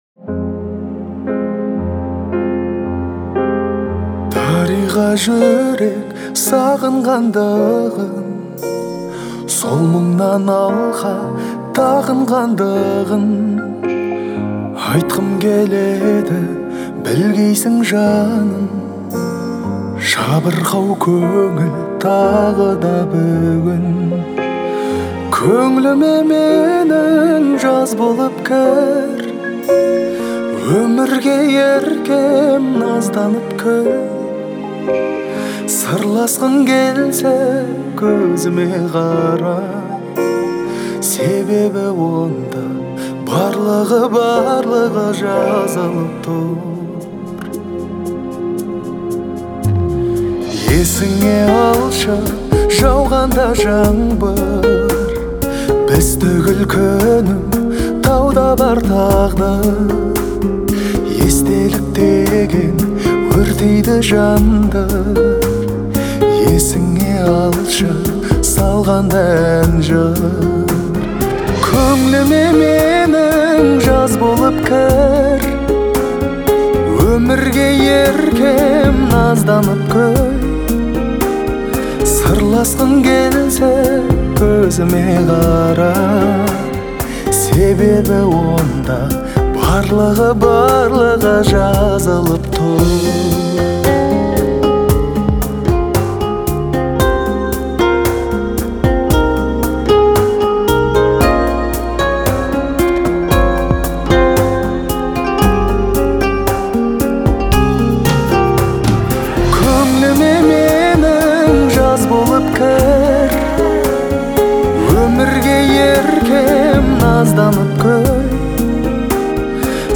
используя мягкий вокал и выразительные мелодии.